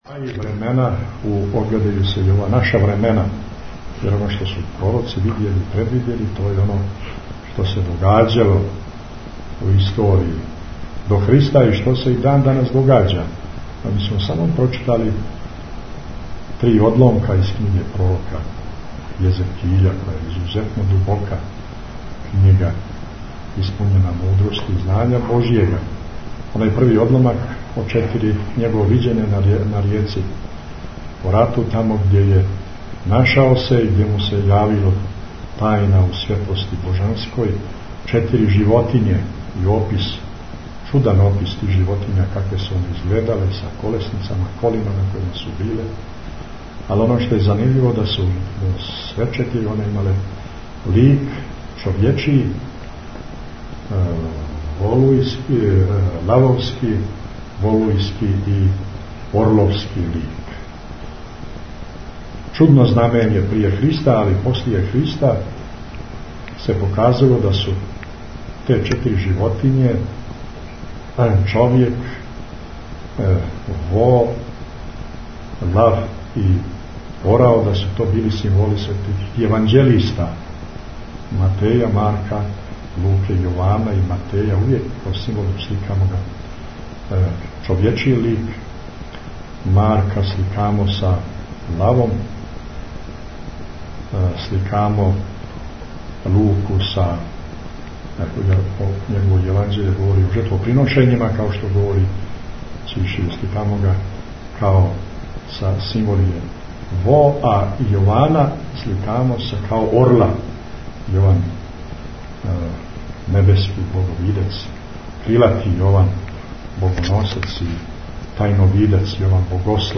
Tagged: Бесједе Наслов: AEM Crnogorsko-primorski Amfilohije Албум: Besjede Година: 2009 Величина: 5:17 минута (929.29 КБ) Формат: MP3 Mono 22kHz 24Kbps (CBR) Бесједа Његовог Високопреосвештенства Архиепископа Цетињског Митрополита Црногогорско - приморског Г. Амфилохија са Вечерње службе коју је у недјељу 2. августа служио у цркви Светог пророка Илије на Царинама на Комовима Кучким.